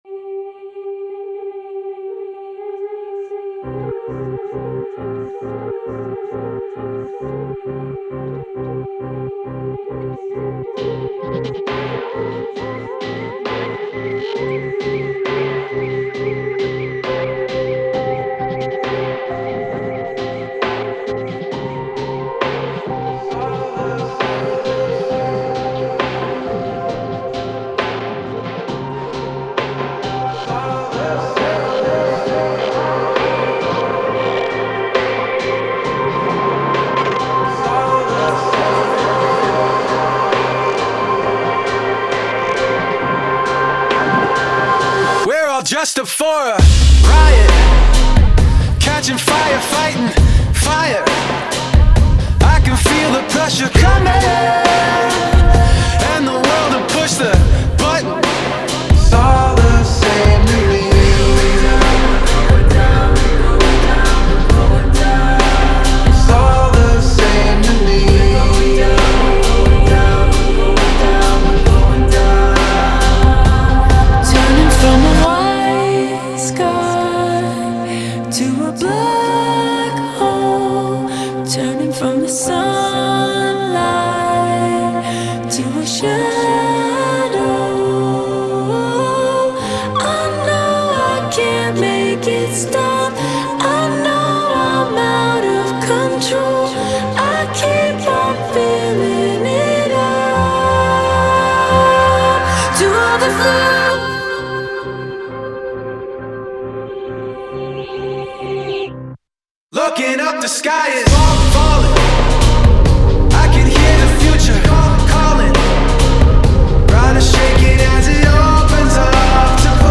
alternative metal nu metal